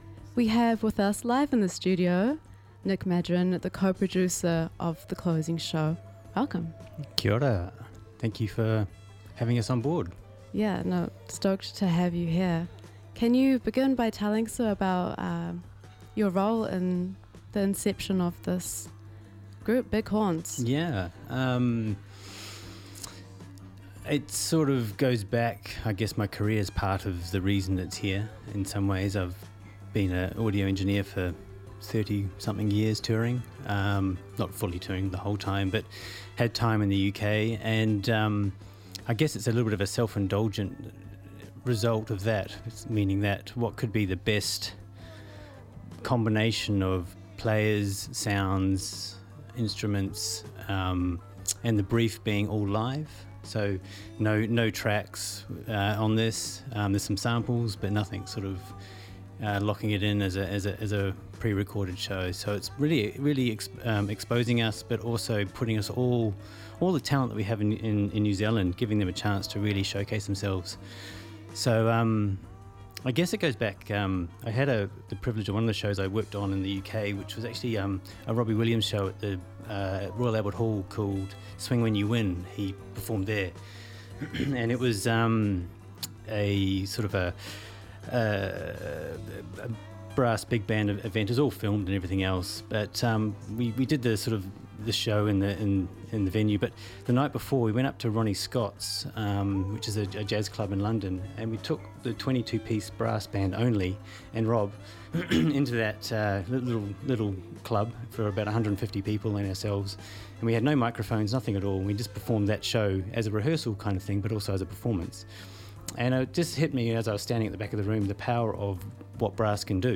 Auckland Arts Festival Guest Interview